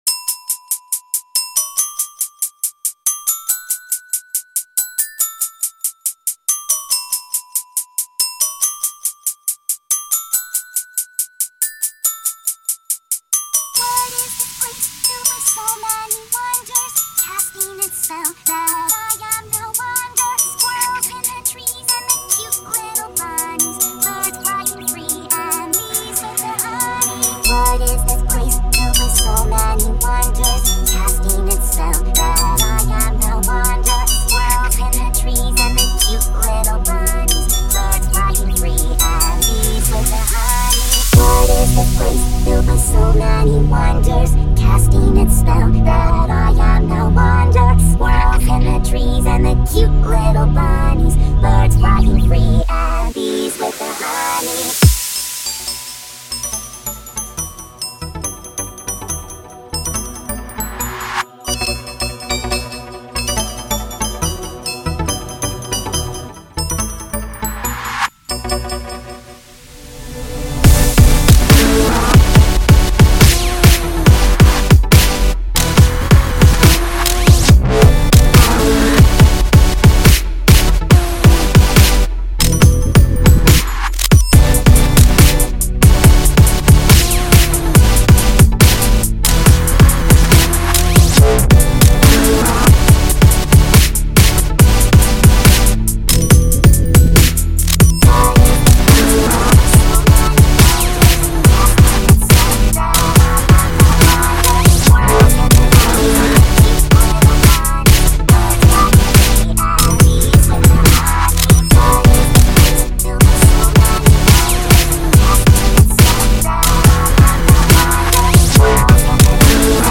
genre:dubstep